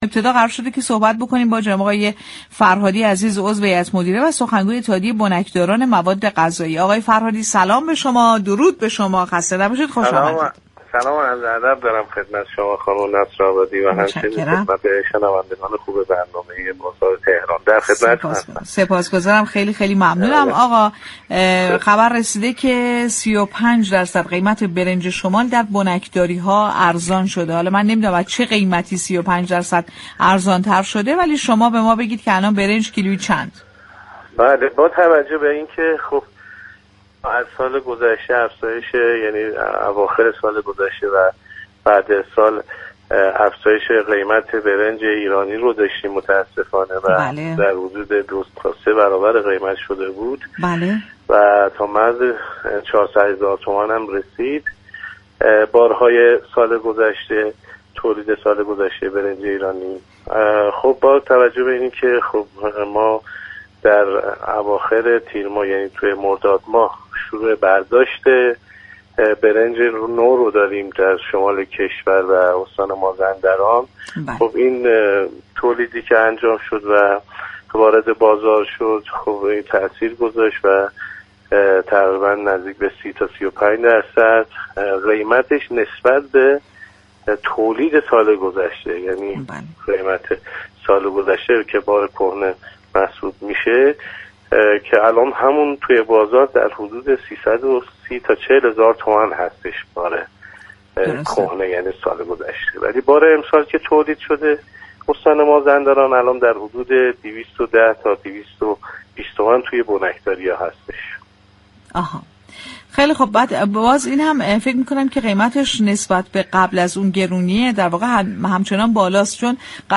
مصاحبه تلفنی